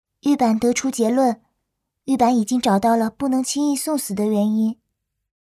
Game VO
Energetic and explosive vocals bring the tsundere personality and powerful aura of Misaka Mikoto to life, perfectly recreating her classic anime portrayal.
The voice actor intentionally uses a flat, controlled delivery to convey the mechanical and emotionless nature of the Sisters, accentuating their distinctive traits.